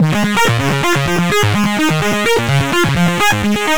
Rasping Arp Bb 127.wav